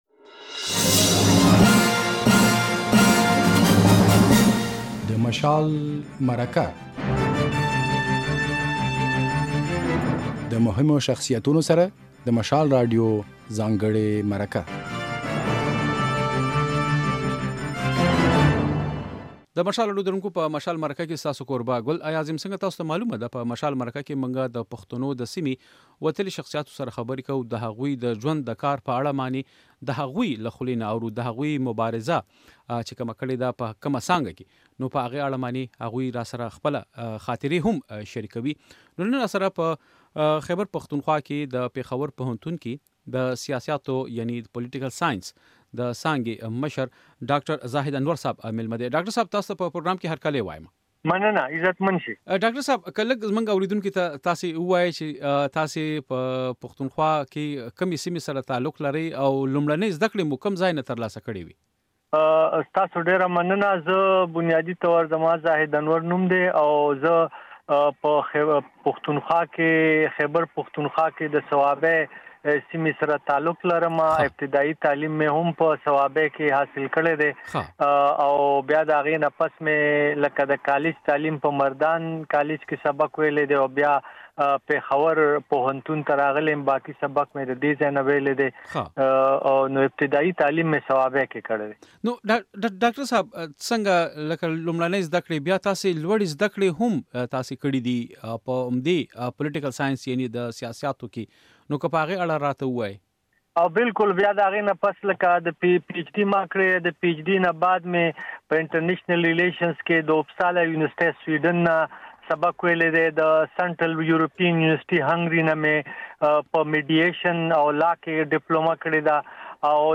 دی وايي، په قبایلي ضلعو کې سیاسي شعور زیات شوی دی. بشپړه مرکه واورئ.